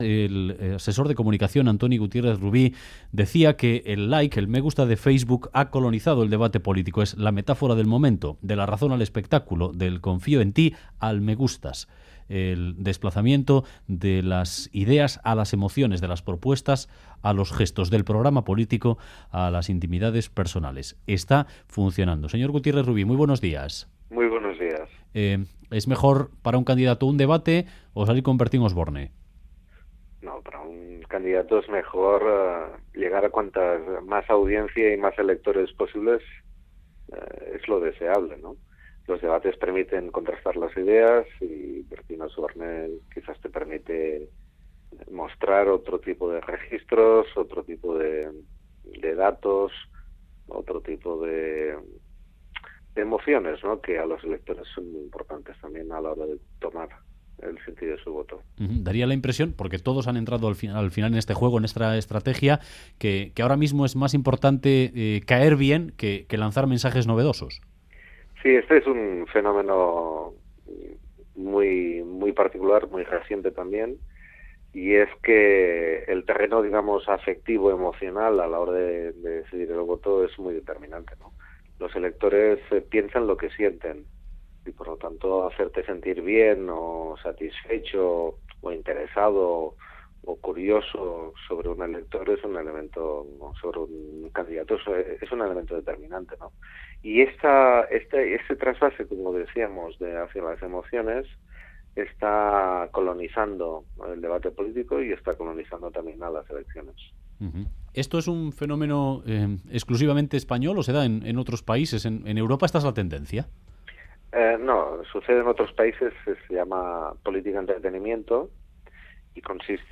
En entrevista al Boulevard de Radio Euskadi